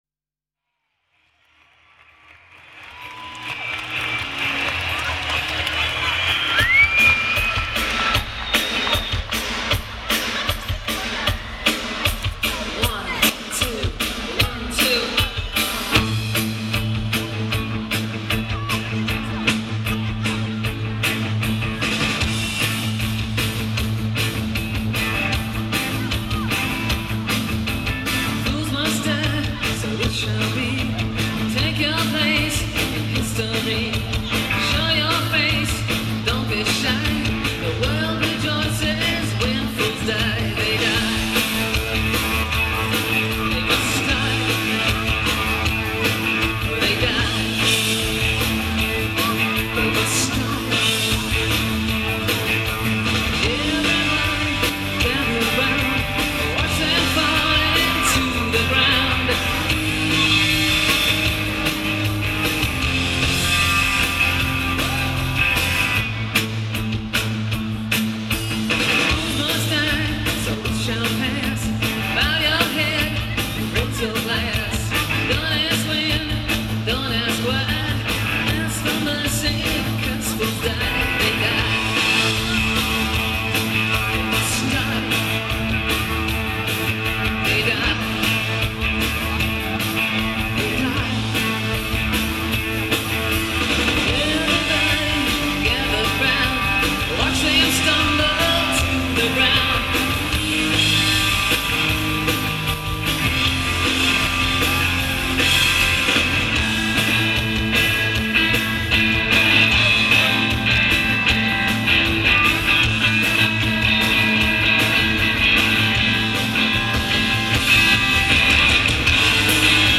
Listen to them perform in Sheffield.